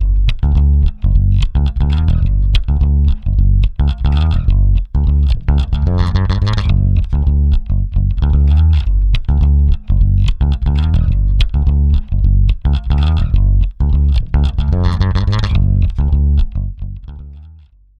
Bass guitar sound at 44'100 Hz sampling frequency 00' 18" alias004 564 KB